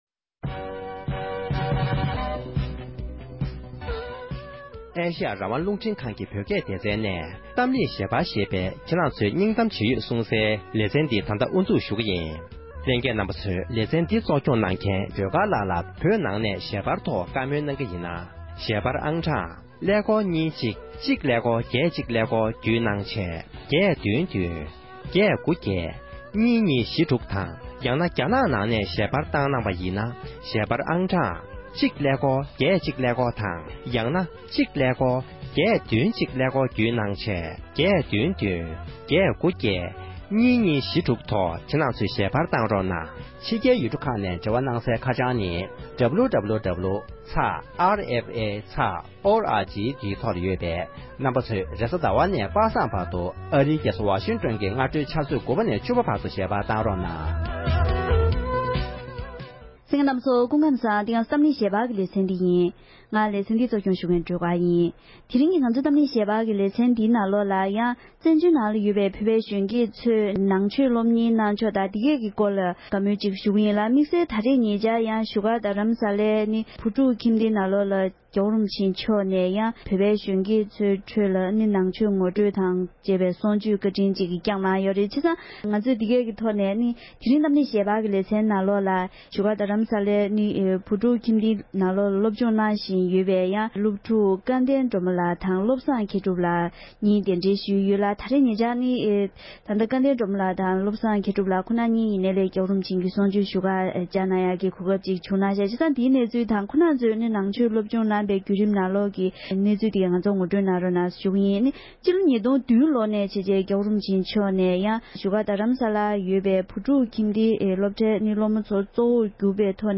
གཏམ་གླེང་